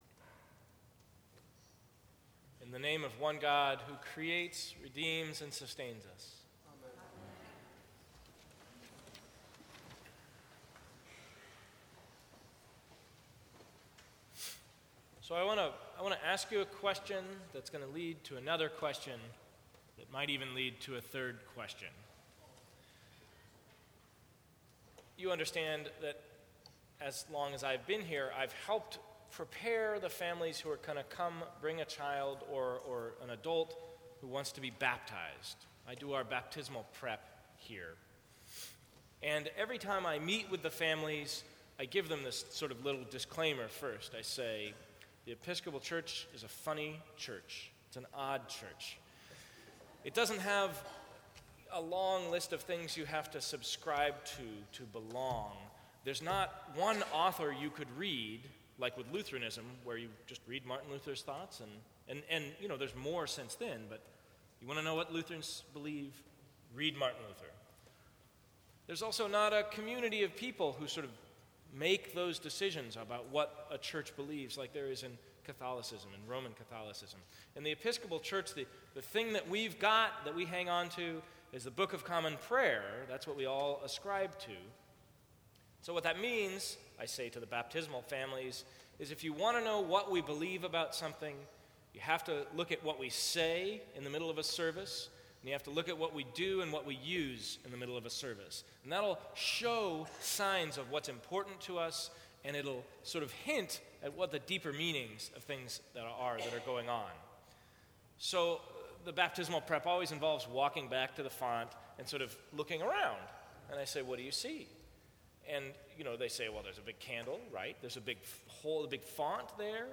Sermons from St. Cross Episcopal Church Why was Jesus baptized?